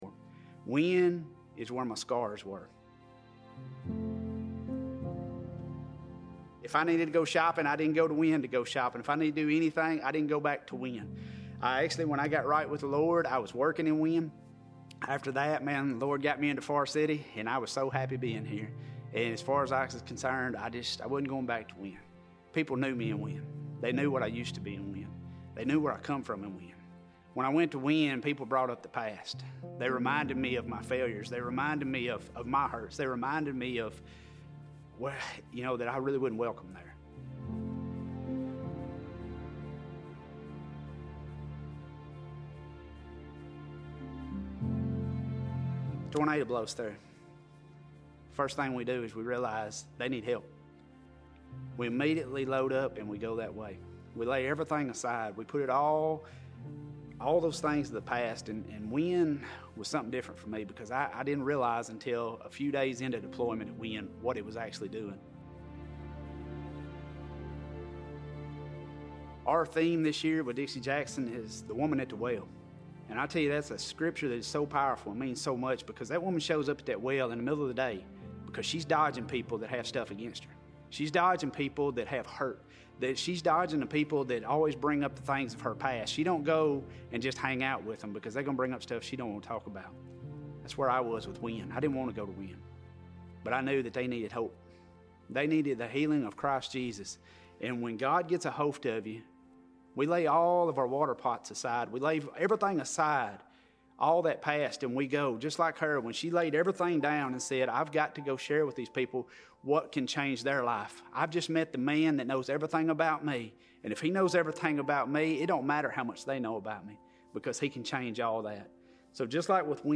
Sunday Sermon September 10, 2023